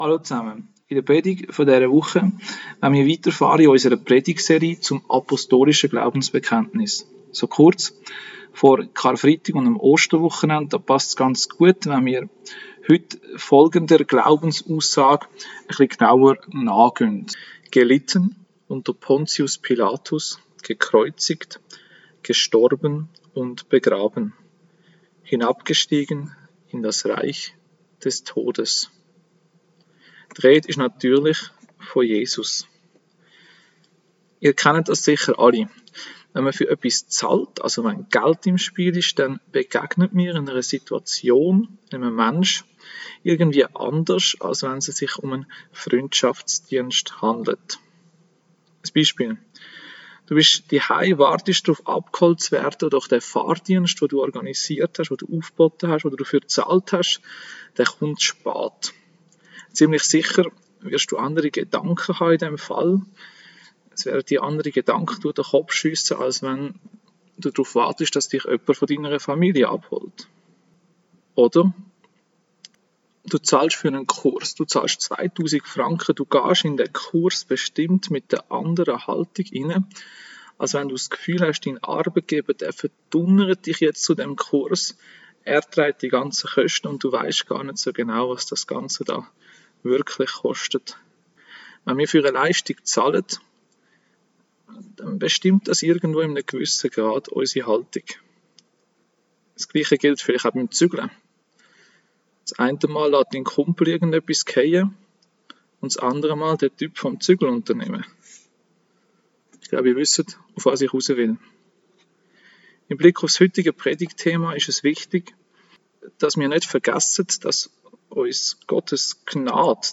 Predigt-Jesu-Leiden-und-Sterben.mp3